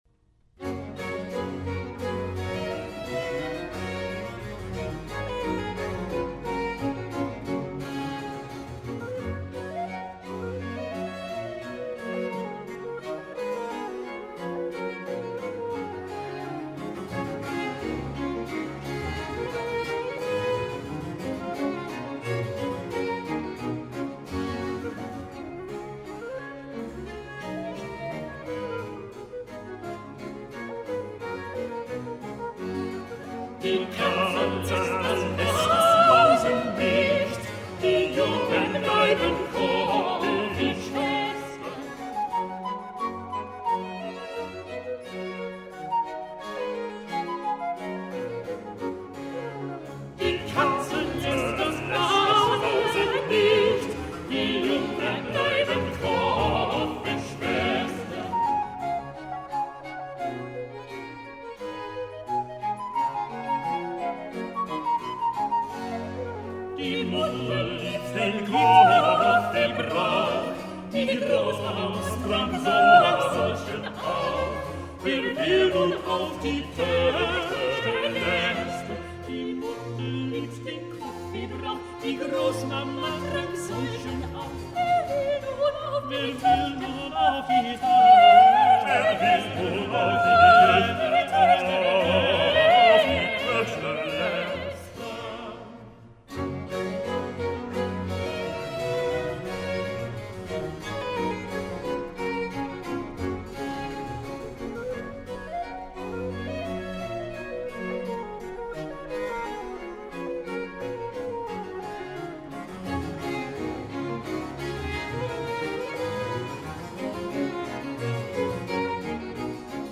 音樂類型：古典音樂